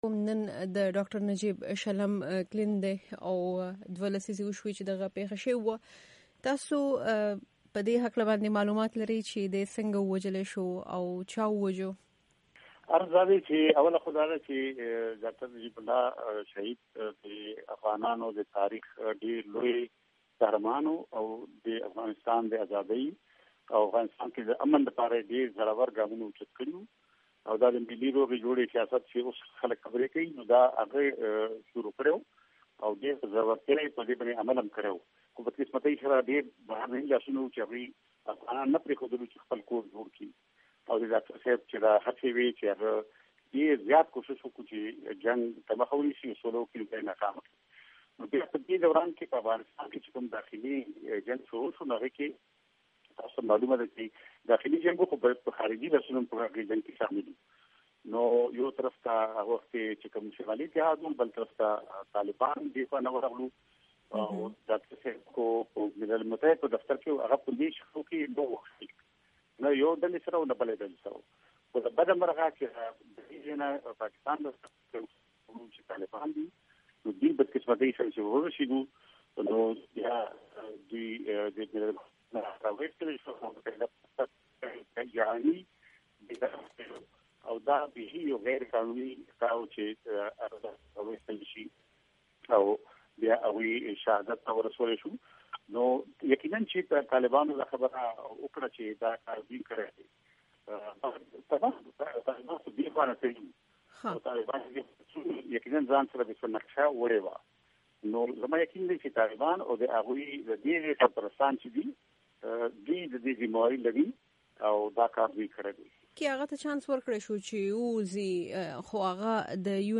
مرکې
د ډاکټر نجیب احمد د وژنې په هکله د افراسیاب خټک سره مرکه